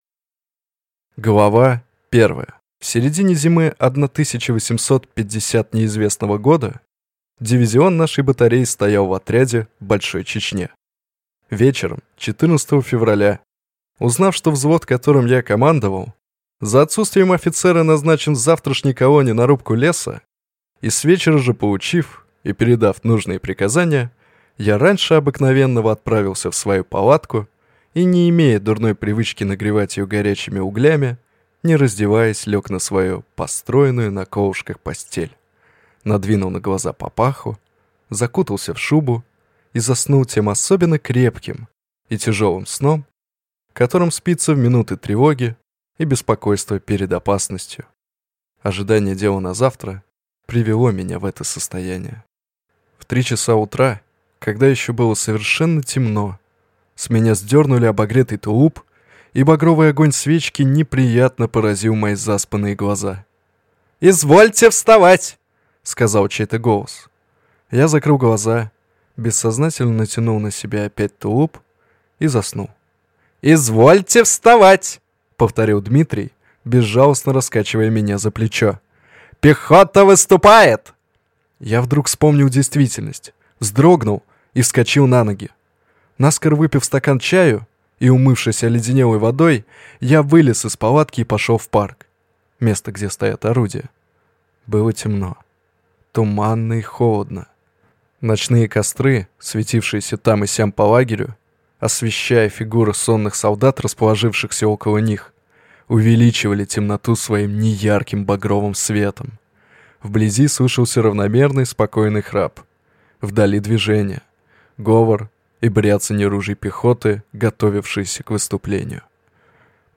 Аудиокнига Рубка леса. Рассказ юнкера | Библиотека аудиокниг